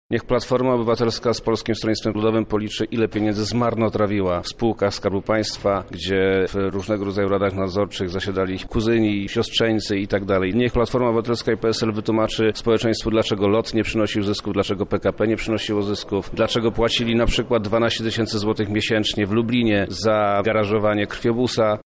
Wojewoda lubelski, Przemysław Czarnek odpiera zarzuty: